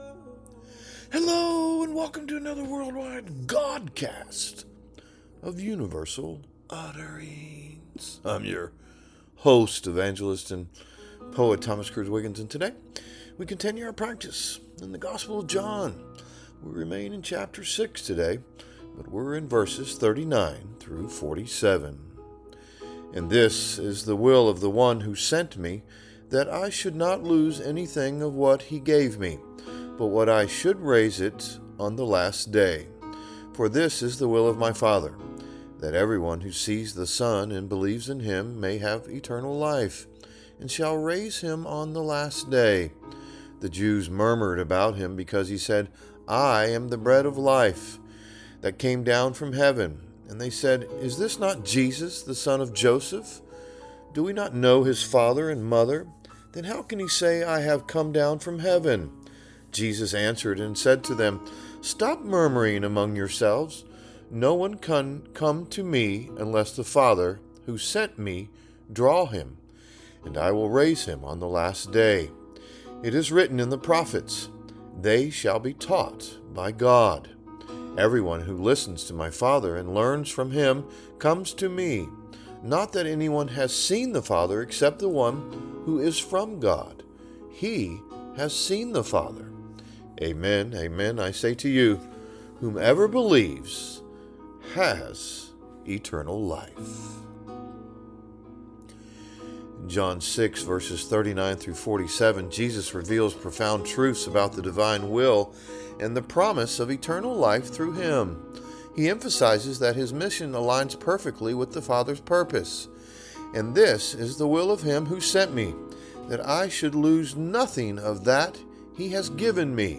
A Godcast